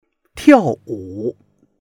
tiao4wu3.mp3